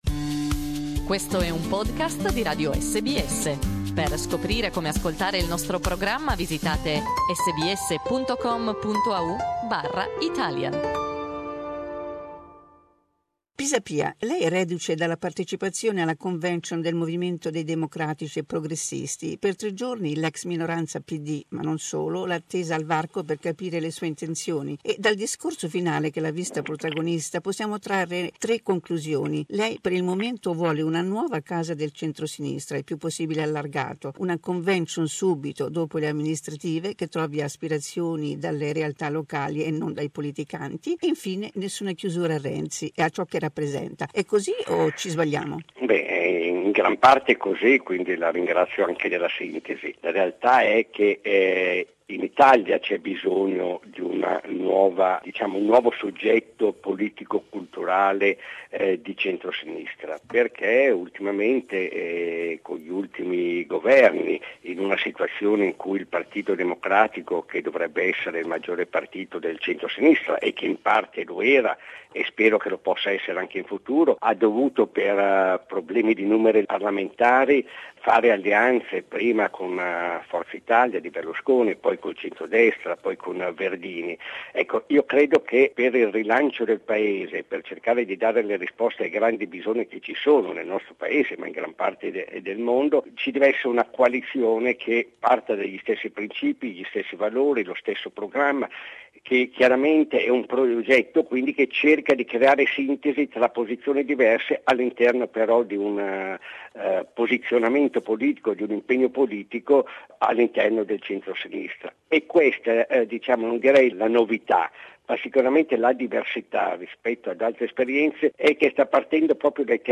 Former Mayor of Milan and leader of the Progressive Movement Giuliano Pisapia, talks about the recent Convention in which he presented his project of a new movement of the centre-left.